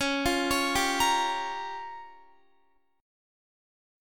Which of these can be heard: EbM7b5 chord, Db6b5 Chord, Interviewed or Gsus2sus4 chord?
Db6b5 Chord